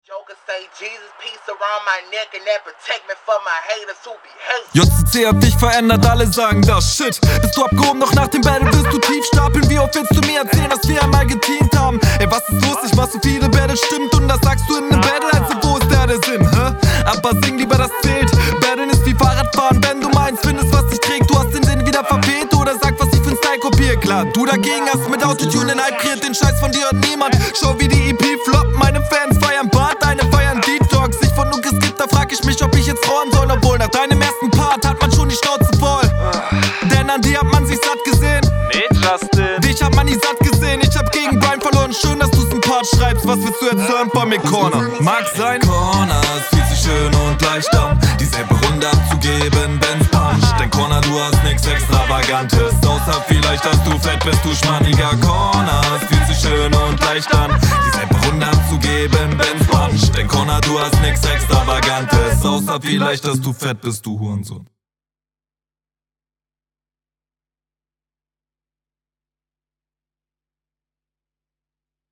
Flowtechnisch ebenfalls mehr als solide.
Oh geil auch du kommst sehr fresh auf dem Beat.